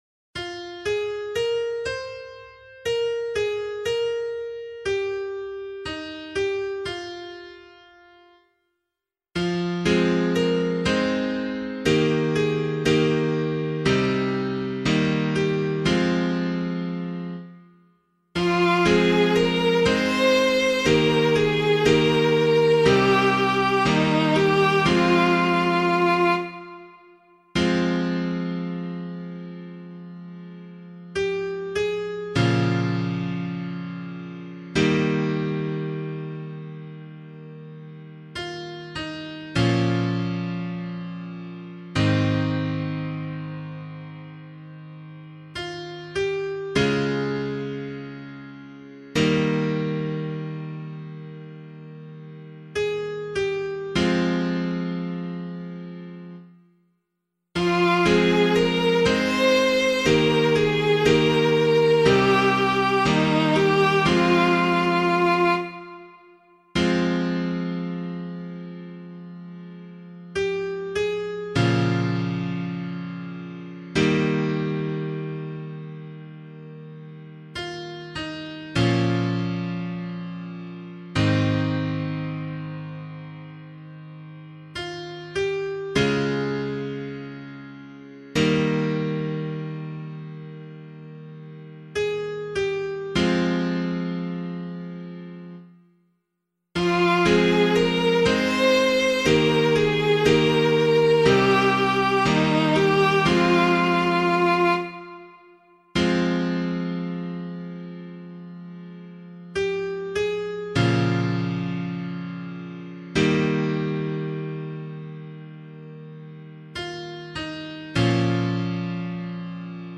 178 Joseph Psalm [LiturgyShare 2 - Oz] - piano.mp3